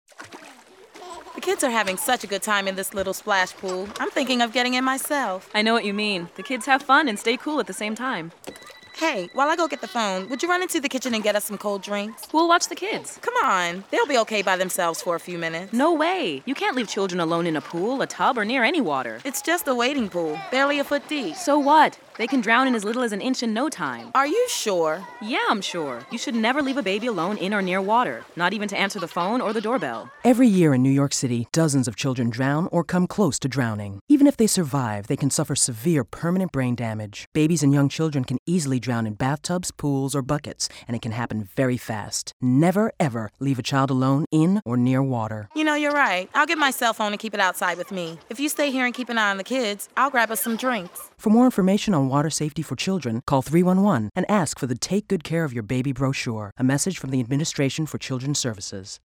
radio_ad_water_safety.mp3